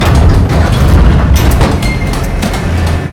dock.ogg